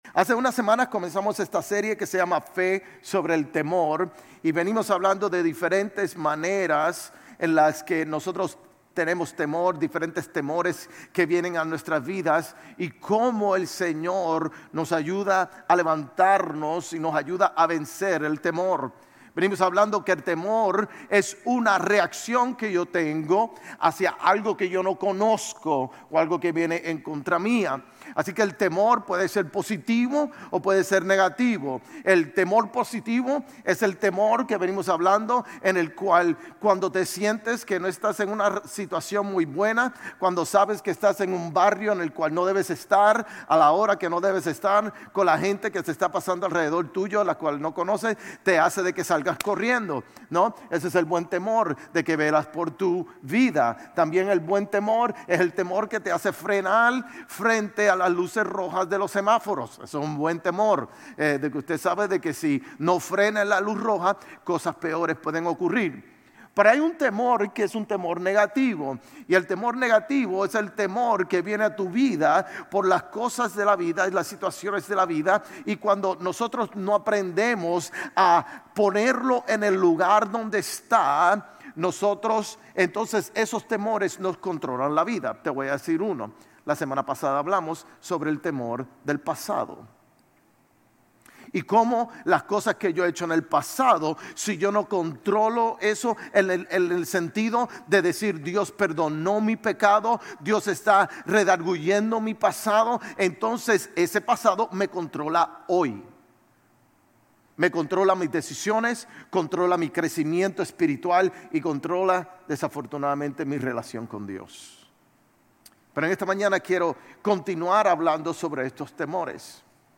Sermones Grace Español 6_8 Grace Espanol Campus Jun 08 2025 | 00:38:53 1x 00:00 / 00:38:53 Subscribe Share RSS Feed Share Link Embed